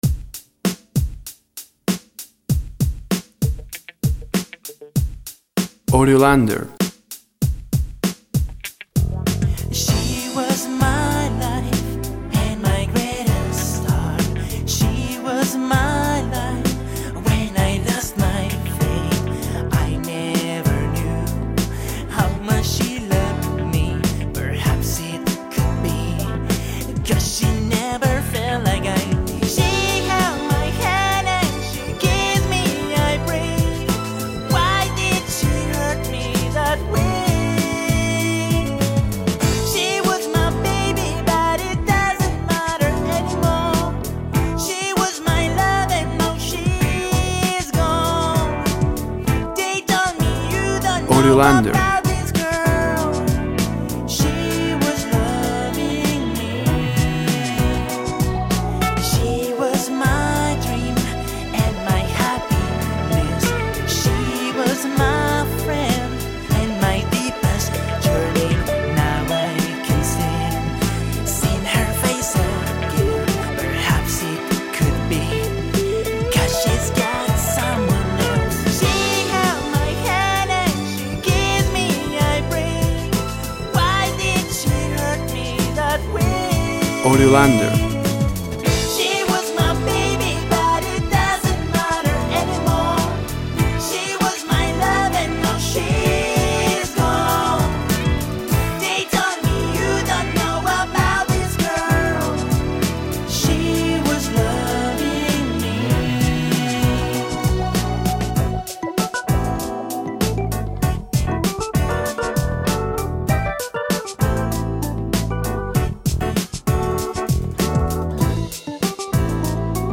Old school vibe, r&b, soul.
Tempo (BPM) 97